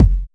• Short Disco Kickdrum Sound F Key 304.wav
Royality free bass drum one shot tuned to the F note. Loudest frequency: 134Hz
short-disco-kickdrum-sound-f-key-304-3LZ.wav